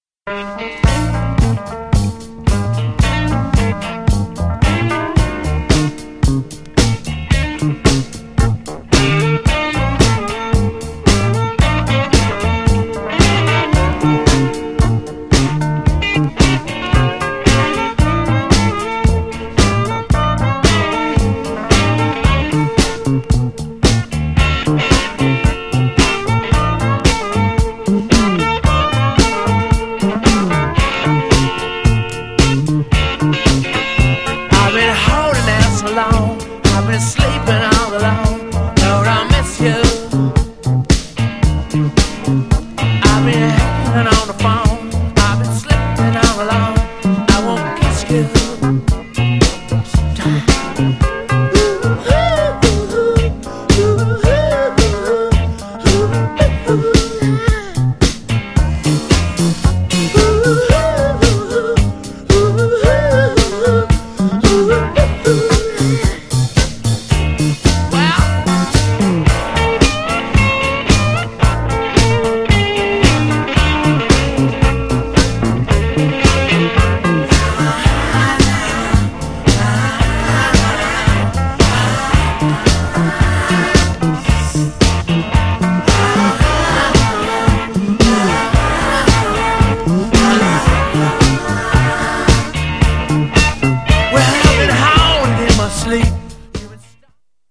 GENRE Dance Classic
BPM 96〜100BPM